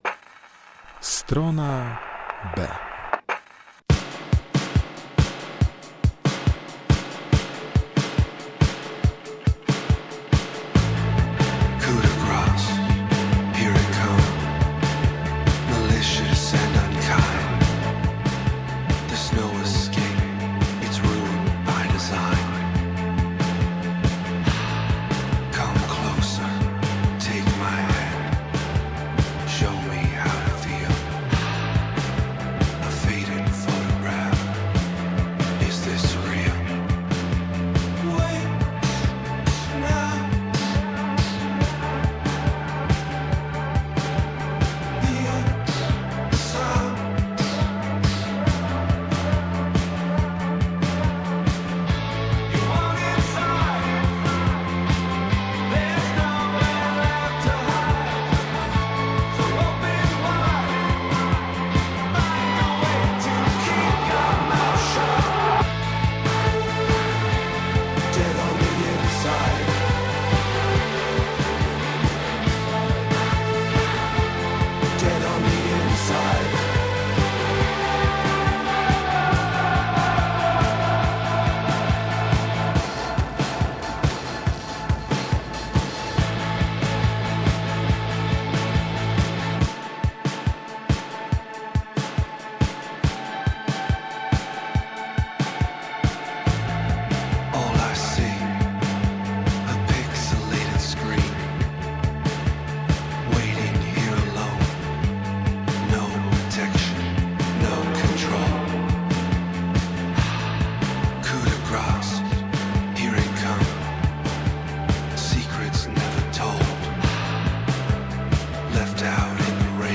Muzyka elektroniczna